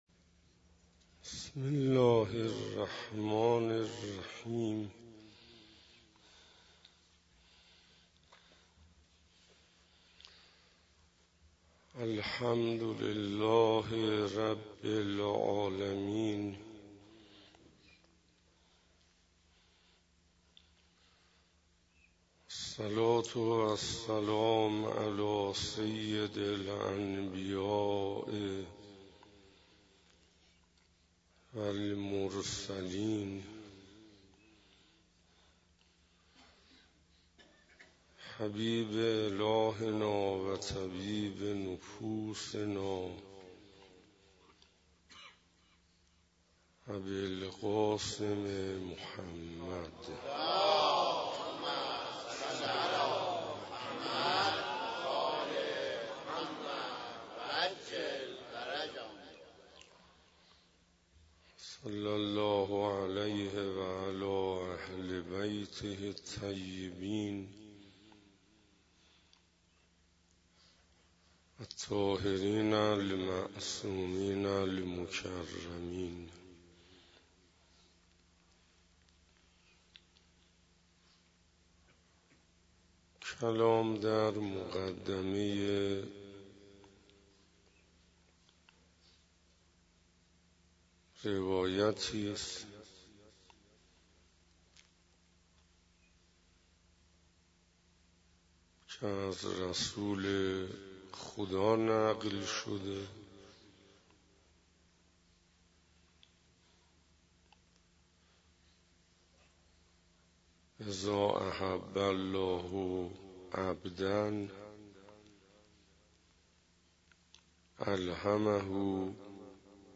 محرم97 - شب چهارم - حسینیه هدایت - محبت خداوند